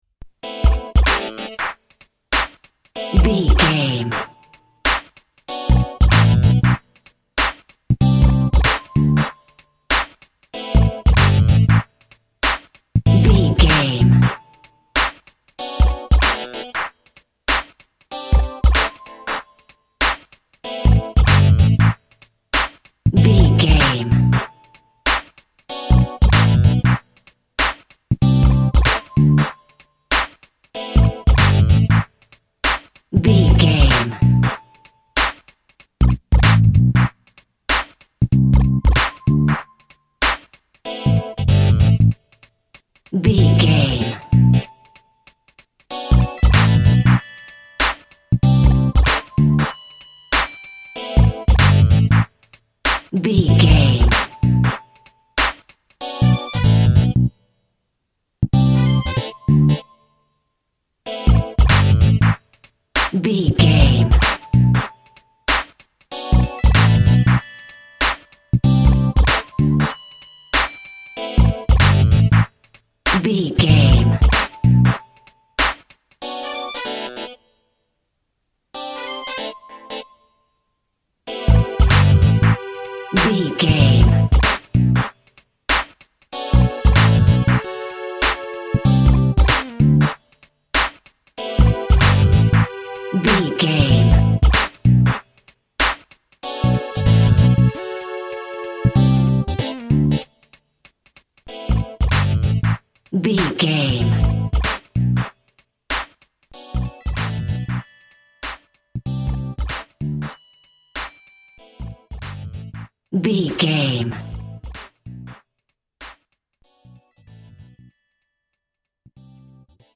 Funky Urban Music Cue.
Aeolian/Minor
hip hop music
synth lead
synth bass
hip hop synths